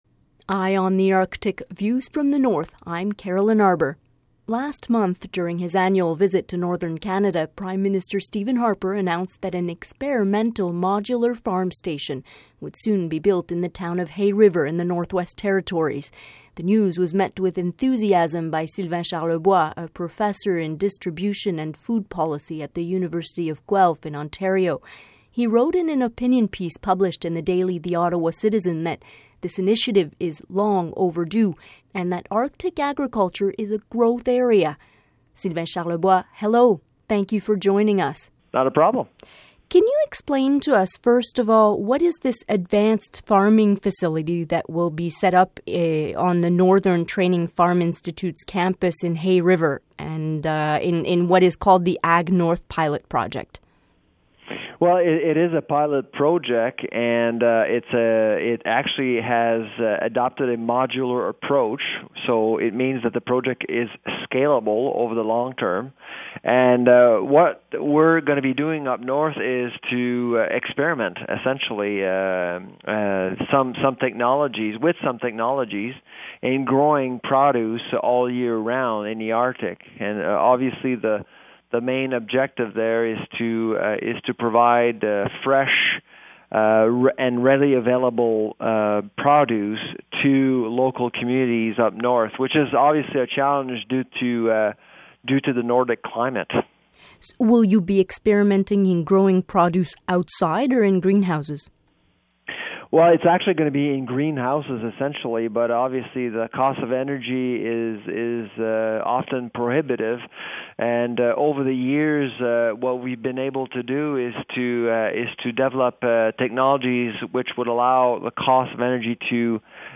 Feature interview: a pilot project towards food sovereignty in Northern Canada